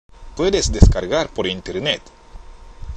＜発音と日本語＞